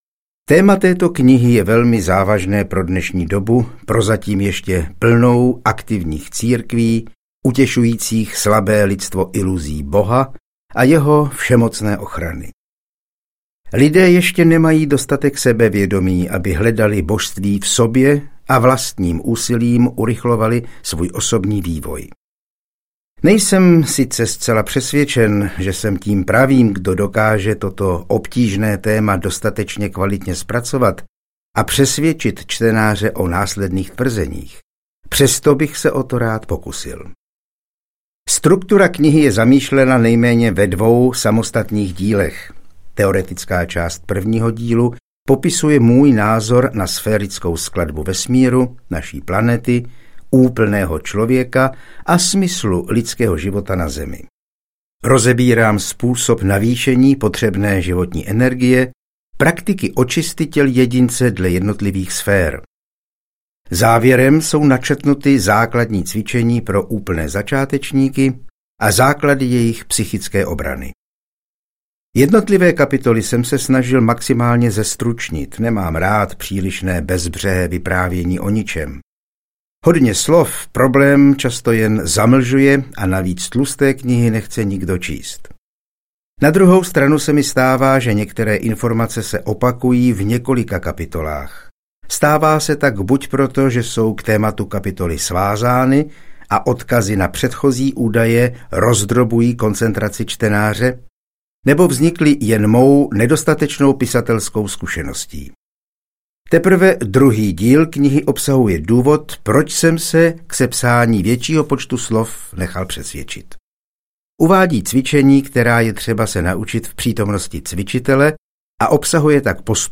Být bohem je lidské audiokniha
Ukázka z knihy